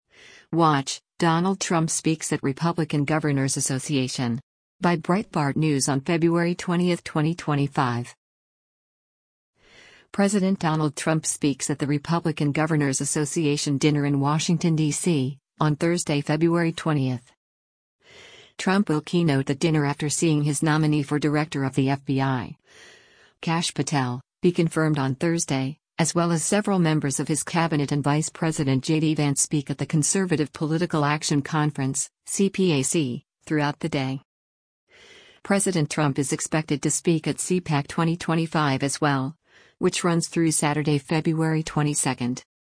President Donald Trump speaks at the Republican Governors Association dinner in Washington, DC, on Thursday, February 20.